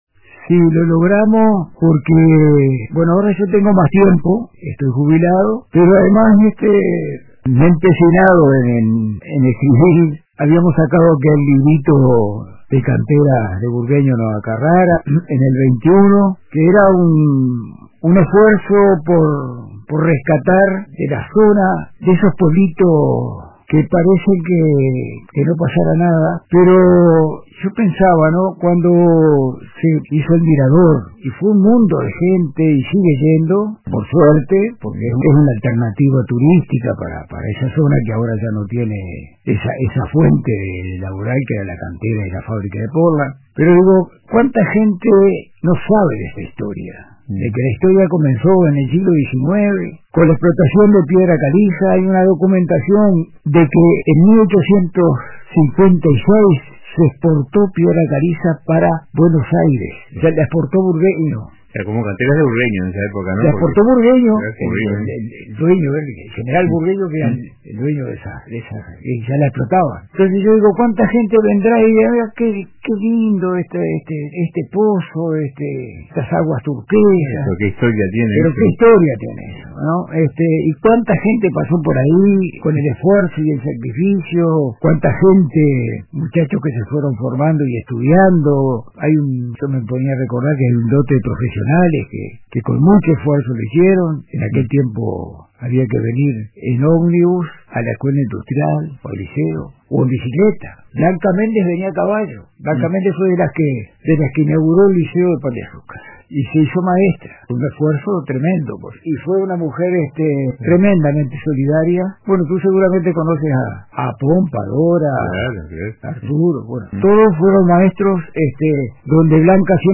En diálogo con el programa Radio con Todos de RBC